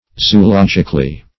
Search Result for " zoologically" : The Collaborative International Dictionary of English v.0.48: Zoologically \Zo`o*log"ic*al*ly\, adv.